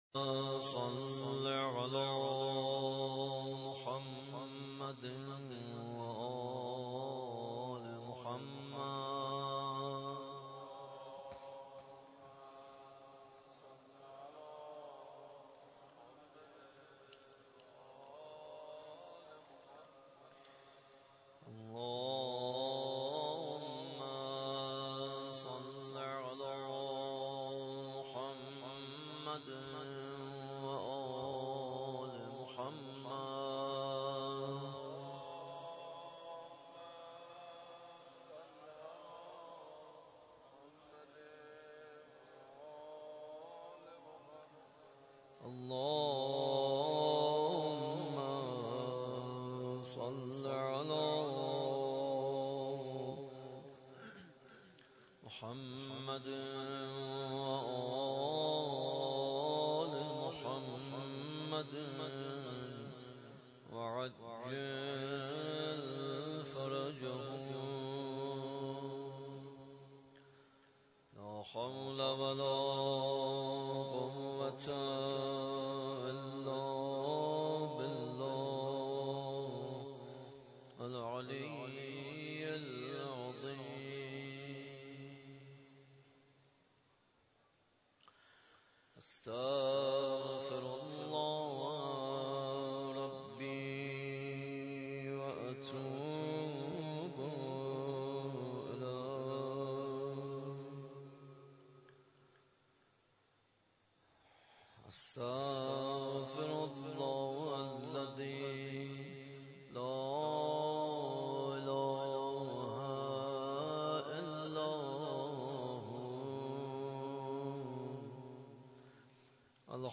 مداحی
در مسجد امام حسین (ع) واقع در میدان امام حسین(ع) برگزار گردید.